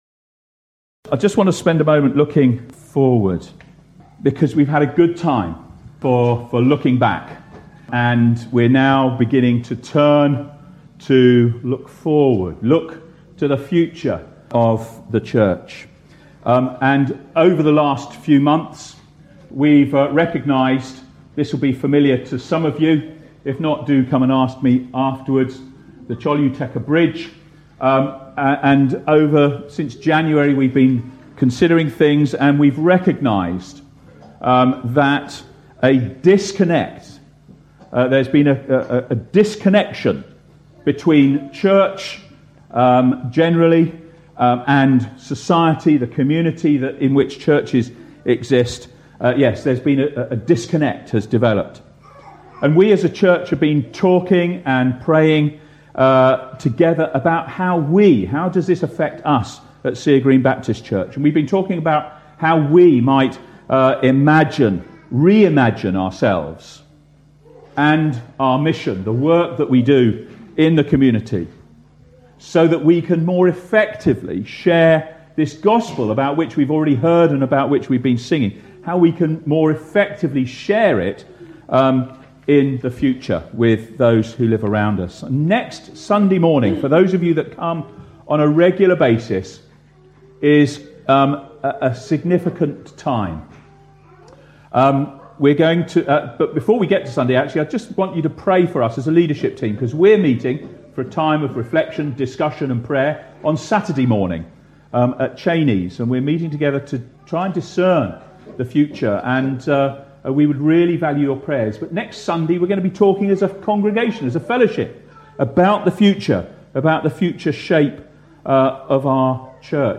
The finale of our month of celebration was on 24th June.